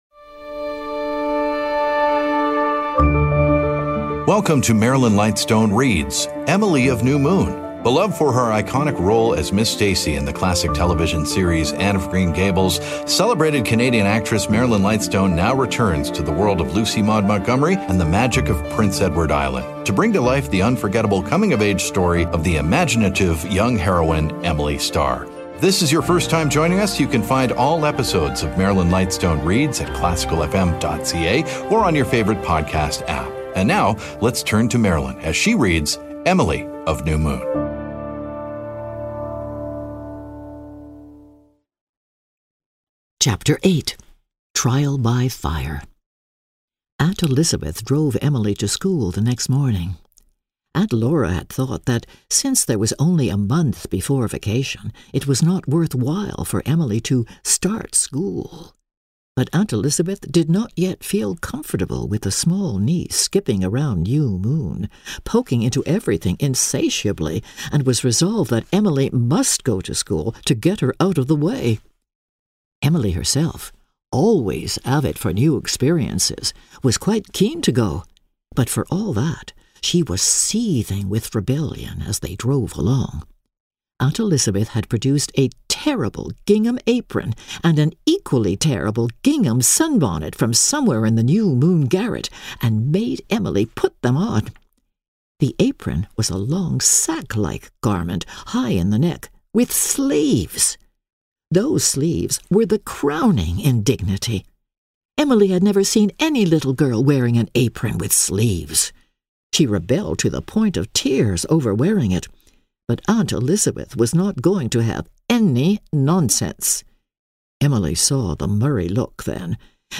Emily Of New Moon: Chapters 8-9 Marilyn Lightstone Reads podcast